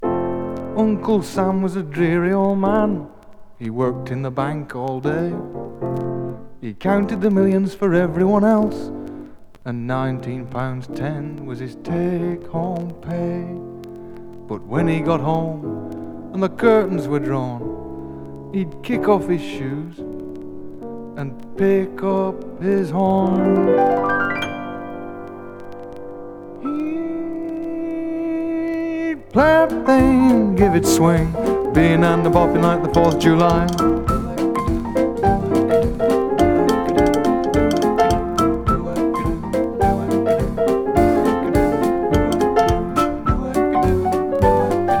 Pop, Rock, Vocal　UK　12inchレコード　33rpm　Stereo